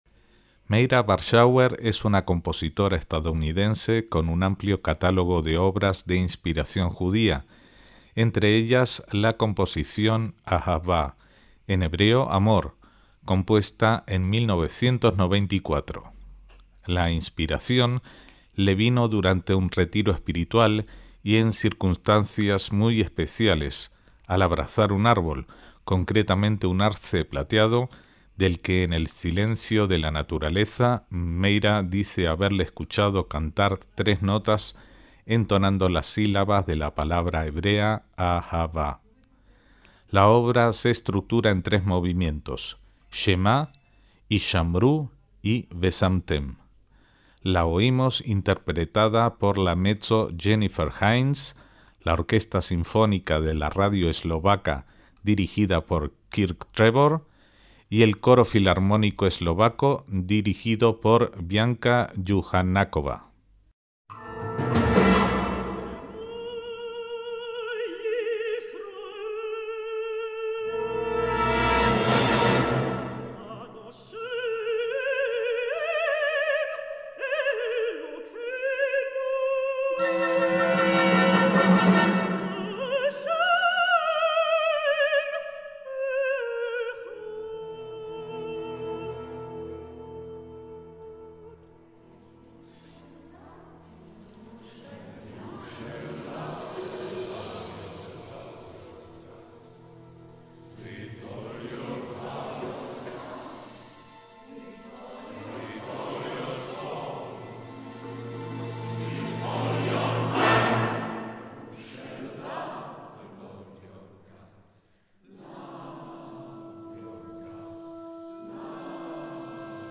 MÚSICA CLÁSICA
junto a la orquesta y coro sinfónicos
mezzo